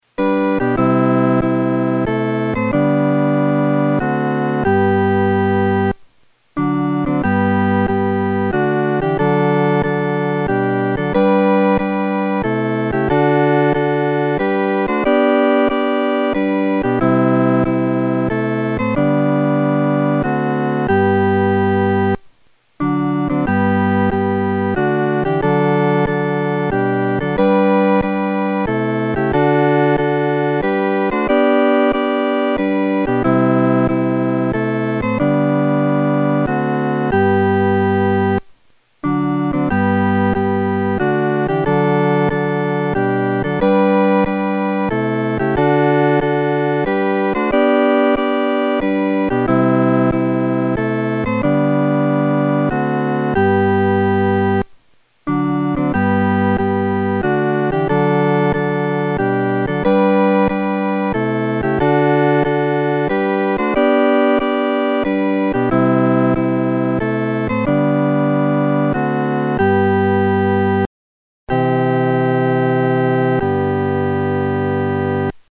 四声